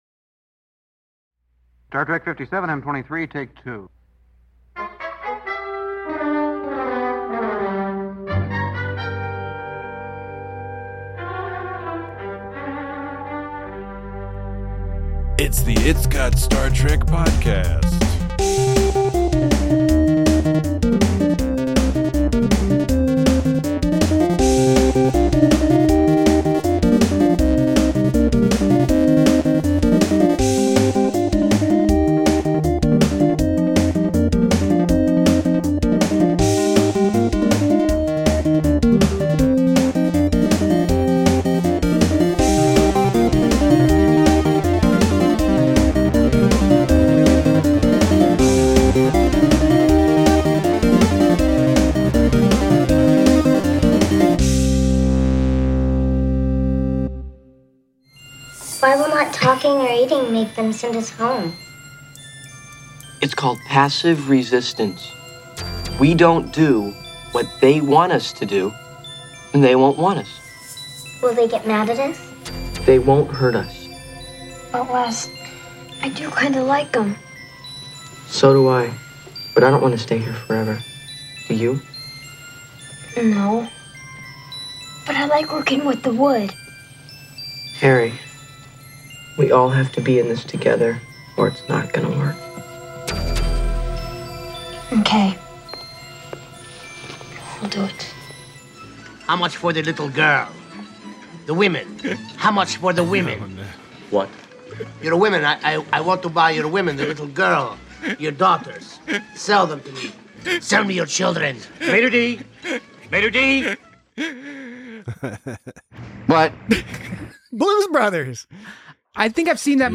Aliens steal some kids from the Enterprise-D and everyone gets all mad about it. Join your maternal hosts as they discuss a goofily-presented story undergirded by solid writing and plot structure.…